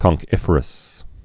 (kŏng-kĭfər-əs)